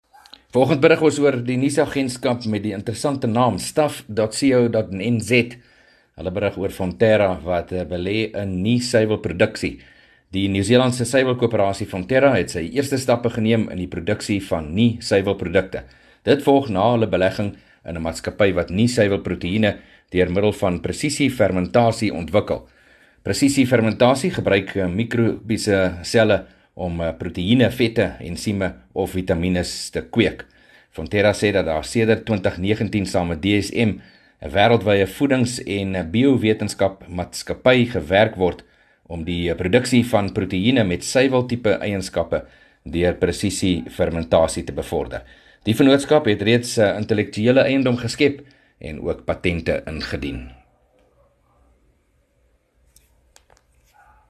7 Nov PM berig oor ‘n suiwelmaatskappy wat begin het met nie-suiwelproduksie